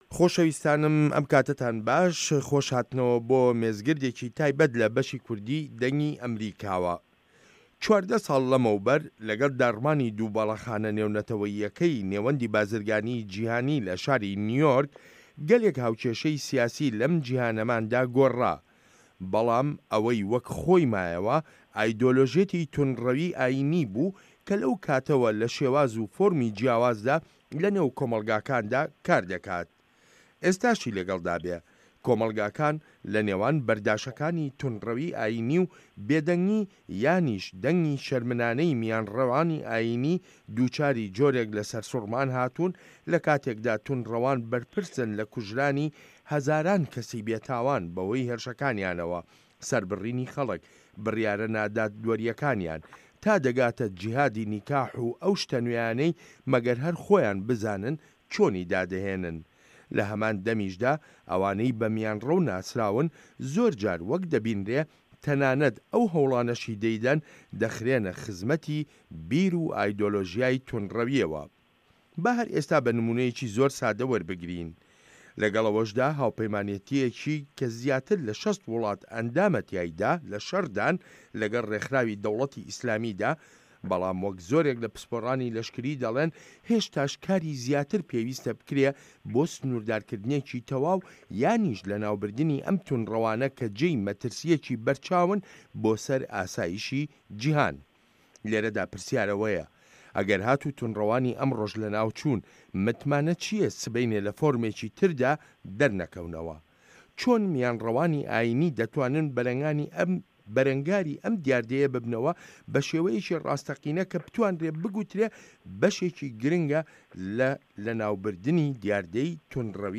مێزگرد: توندڕەویی ئاینی لە نێوان میانڕەویی و دیموکراسیدا